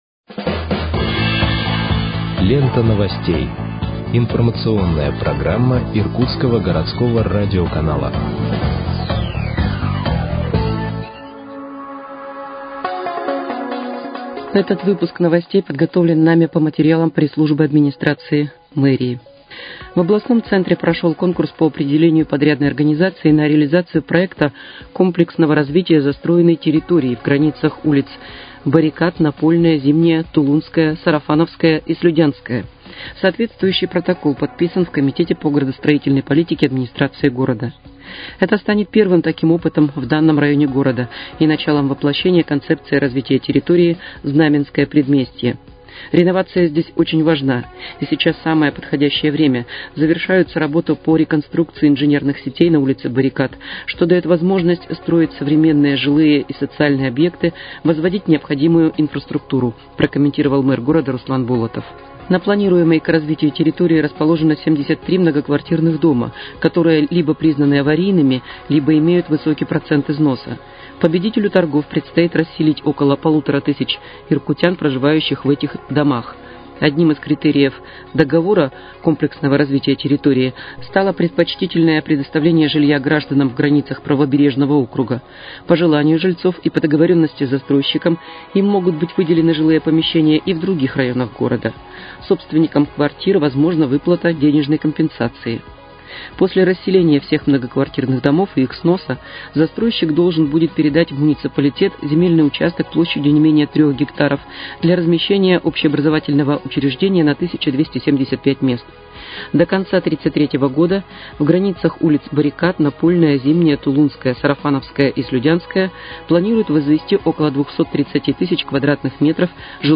Выпуск новостей в подкастах газеты «Иркутск» от 10.10.2025 № 1